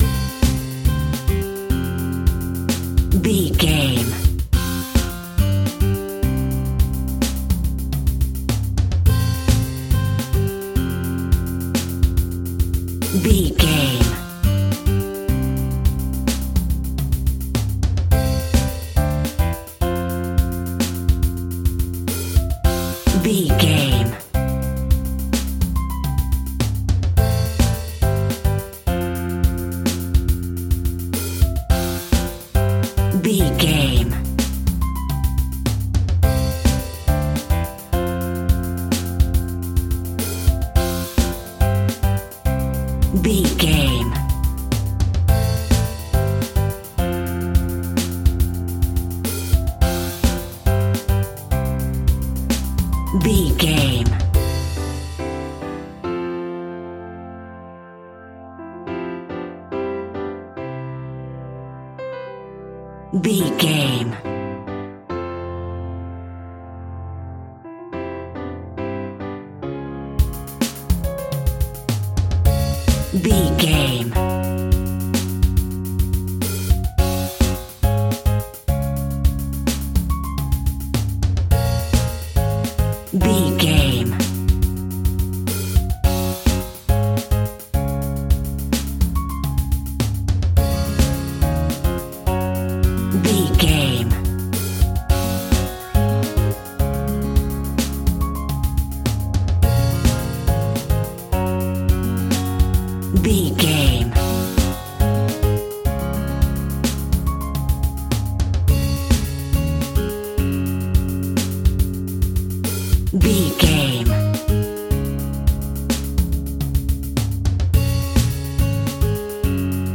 Music From The Theatre.
Ionian/Major
pop rock
indie pop
fun
energetic
uplifting
cheesy
synths
drums
bass
guitar
piano